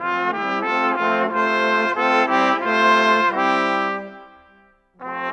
Rock-Pop 22 Trombones _ Trumpets 01.wav